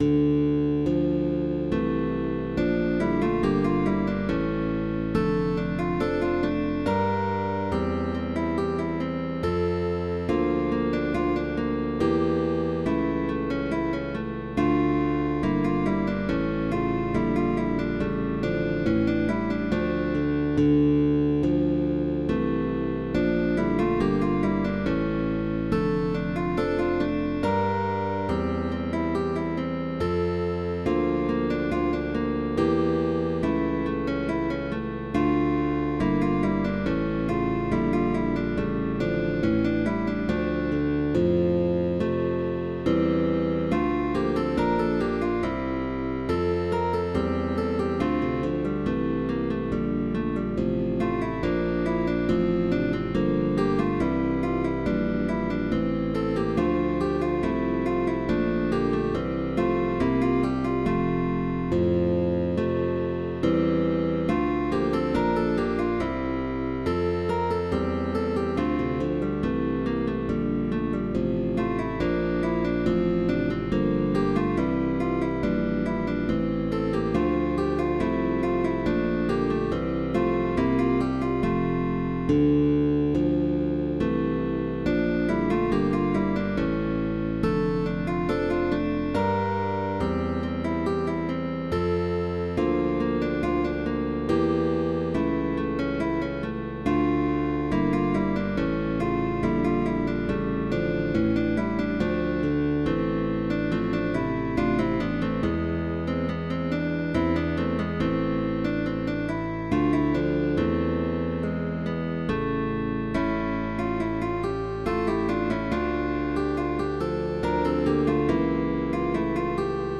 Con bajo opcional, Válido para orquesta de guitarras.